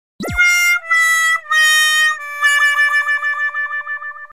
Epic Fail Meme Sound sound effects free download